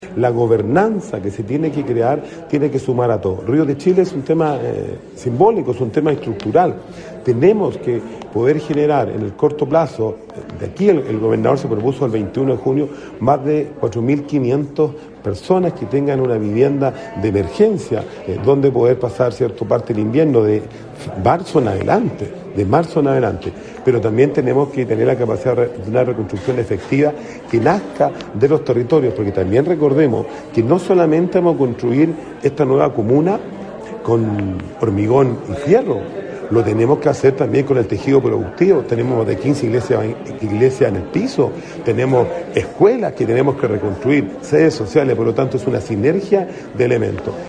En tiempos de resiliencia y reconstrucción: Desafíos del desarrollo urbano y rural para el Chile del 2050 es el título del seminario organizado por la Asociación Chilena de Municipalidades y que se desarrollará en la Universidad del Bío-Bío hasta este viernes.
Rodrigo Vera, alcalde de Penco, expuso en el primer día del seminario acerca de la propuesta de reconstrucción de la comuna, oportunidad en la que recalcó la necesidad de contar con una gobernanza, que incorpore a los vecinos y las autoridades, pero también al sector privado, la academia, las fuerzas armadas desplegadas en el territorio y todos los actores, entendiendo que el desafío es “reconstruir una nueva comuna no solo con hormigón y fierro, también con el tejido productivo”.